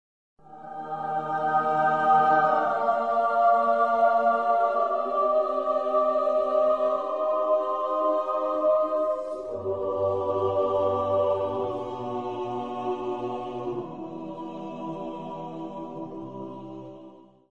На этой странице собраны звуки души — необычные аудиокомпозиции, отражающие тонкие эмоциональные состояния.